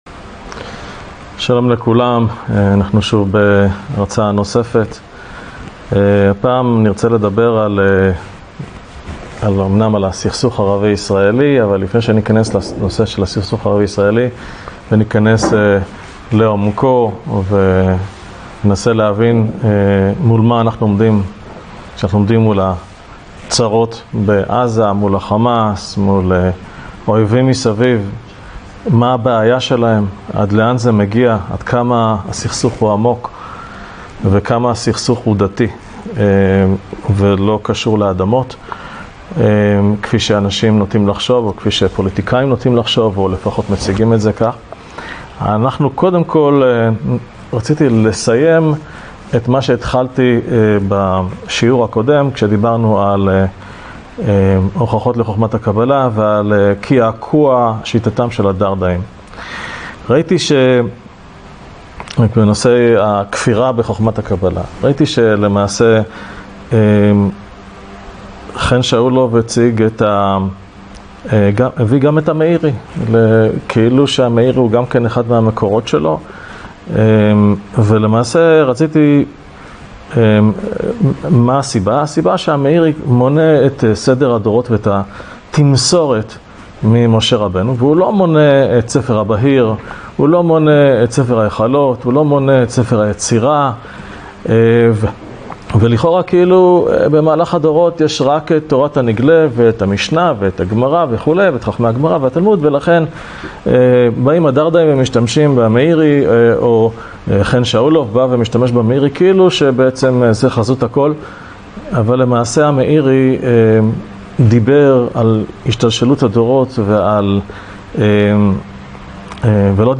A thought-provoking lecture analyzing the Arab-Israeli conflict as a religious and existential struggle, not just a territorial dispute. The speaker examines Jewish tradition, history, and the motivations of Israel’s adversaries, concluding that only spiritual awakening and faith can lead to lasting peace.